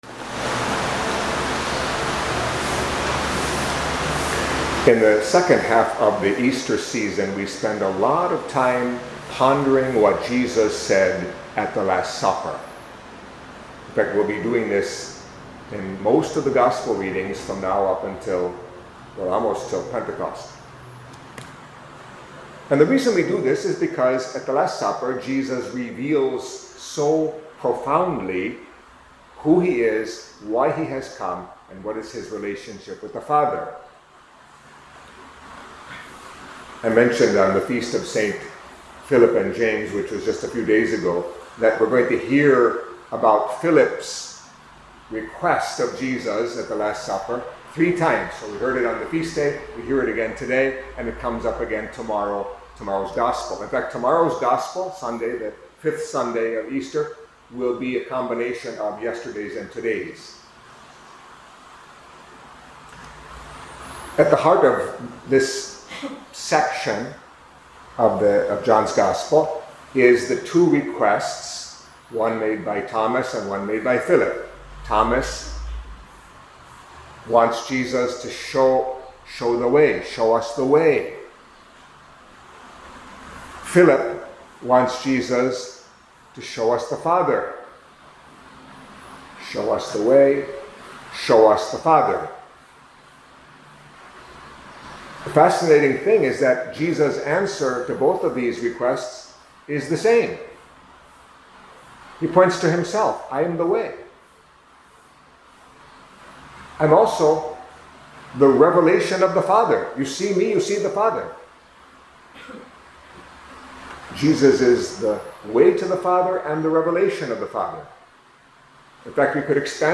Catholic Mass homily for Saturday of the Fourth Week of Easter